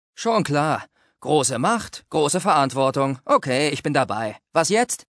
picture x Computer: